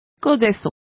Complete with pronunciation of the translation of laburnum.